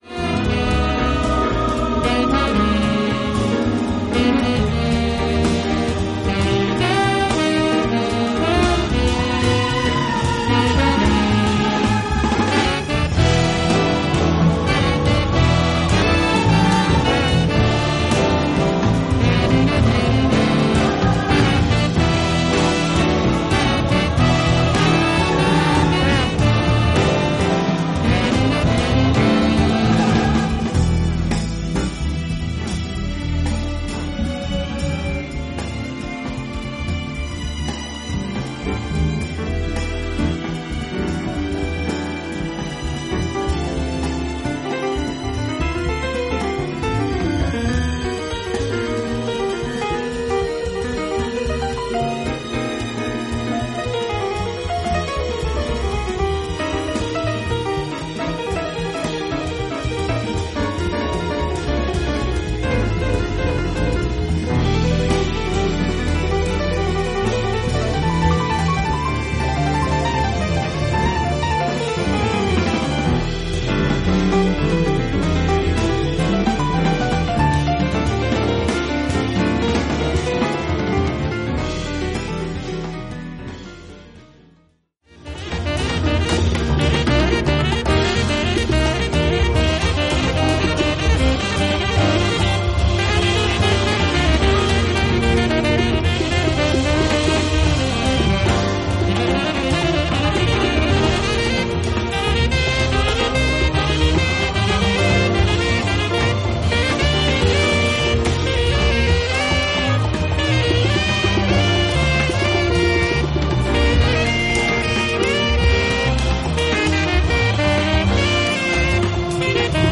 スピリチュアル〜バップ〜アフロが見事に共存する、ジャズ新世紀の幕開けとも言える素晴らしい作品です。